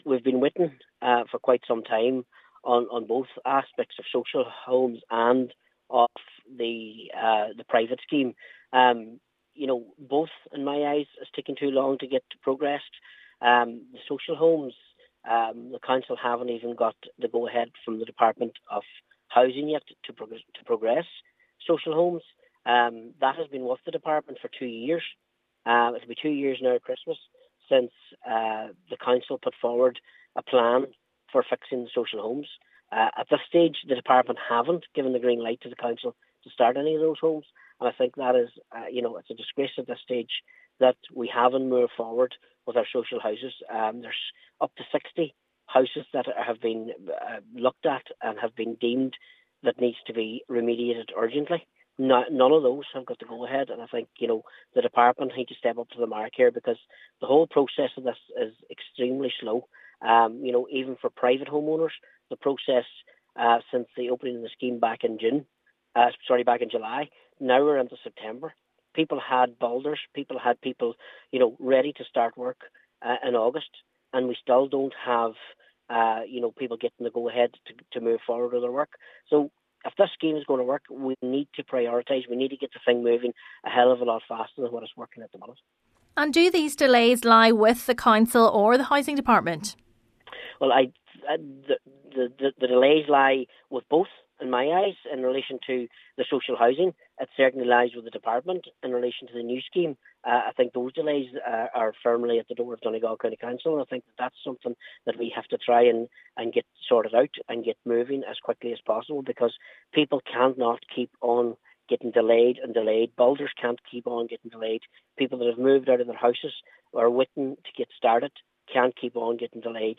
Councillor Martin McDermott says serious questions need to be answered over the timelines applications are being dealt with as homeowners have waited long enough: